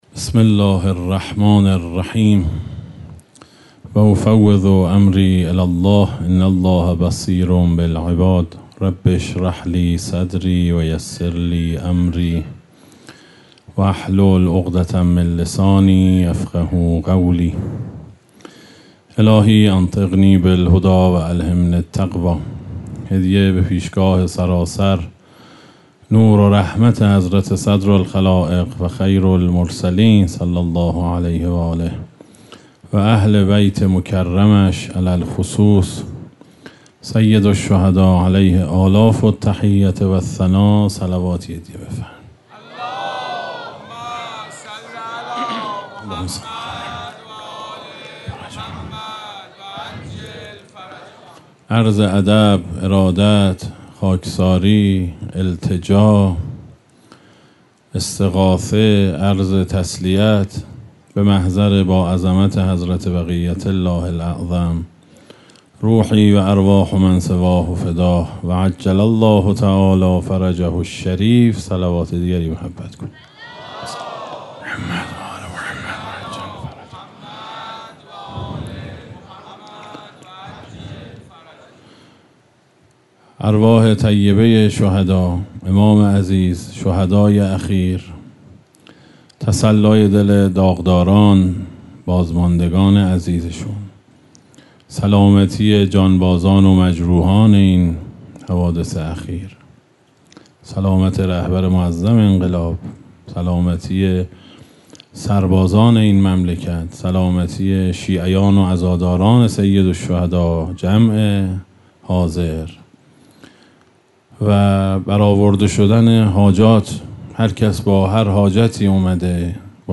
در هیئت محترم حضرت عبدالله بن الحسن علیهما السلام
سخنرانی